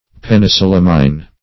Penicillamine \Pen`i*cil"lam*ine\, n. (Chem., Med.)